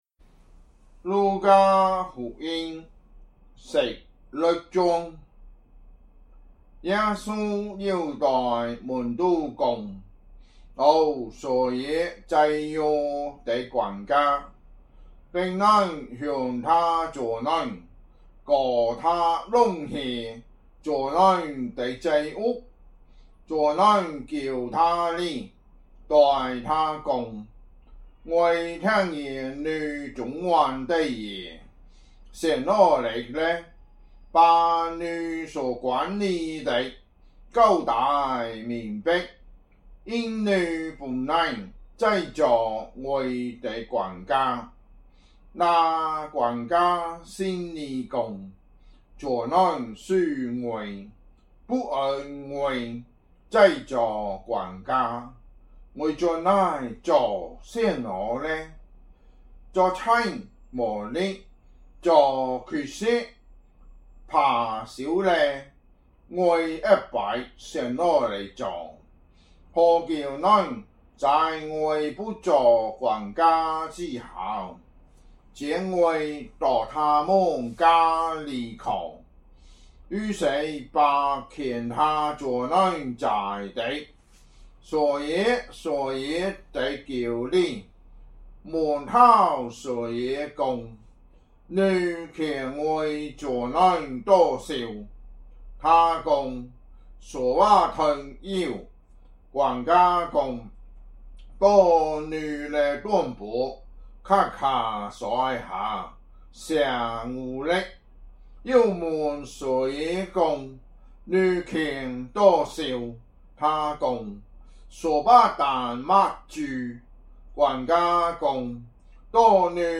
福州話有聲聖經 路加福音 16章